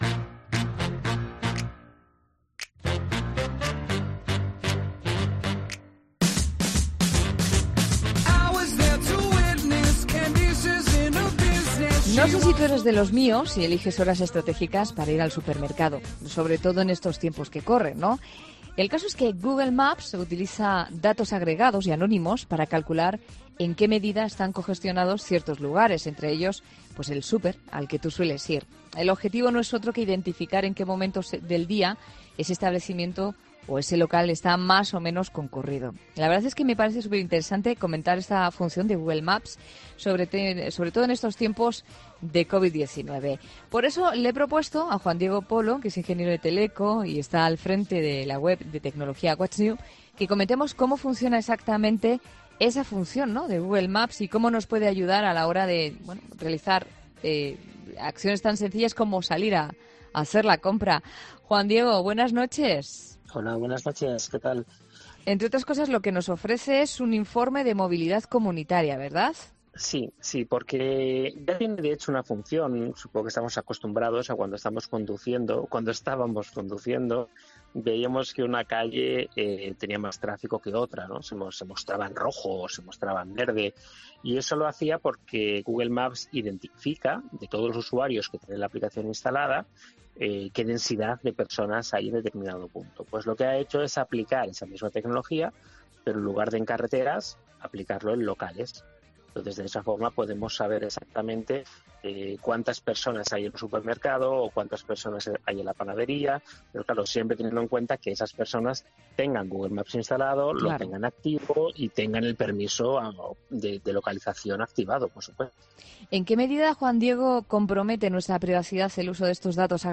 'La Noche' de COPE es un programa que mira la actualidad de cada día con ojos curiosos e inquietos, y en el que el tema principal de cada día, aquel del que todo el mundo habla, se ve desde un punto de vista distinto.